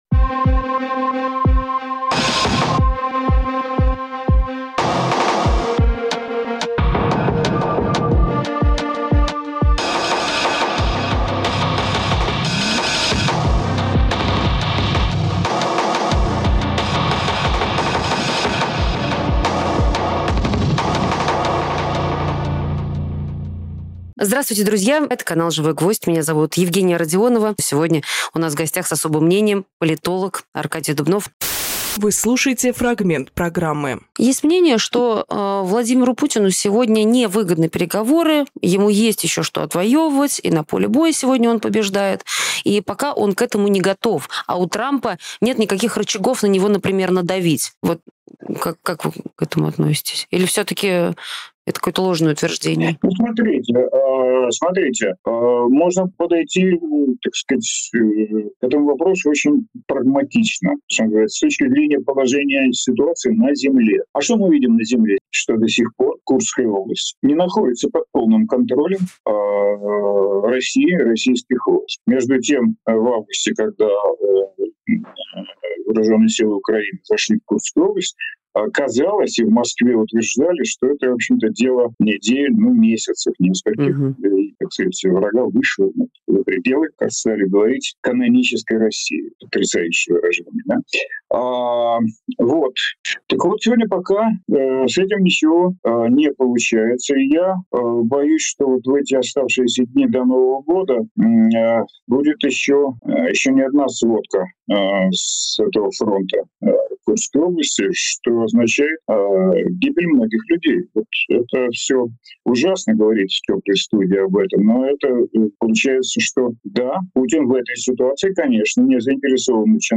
Фрагмент эфира от 24.12.24